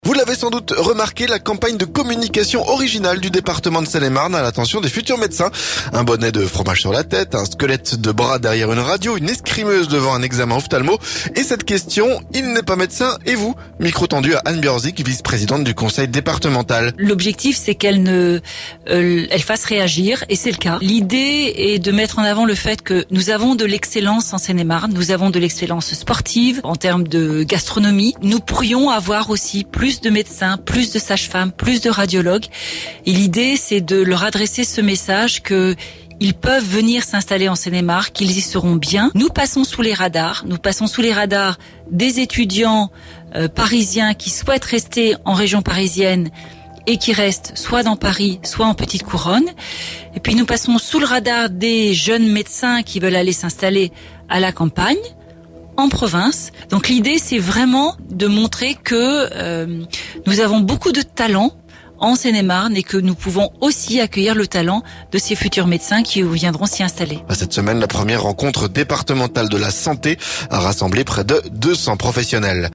Micro tendu à Anne Gbiorzczyk, vice-présidente du conseil départemental en charge de la santé.